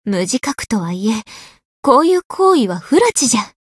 灵魂潮汐-蕖灵-互动-不耐烦的反馈2.ogg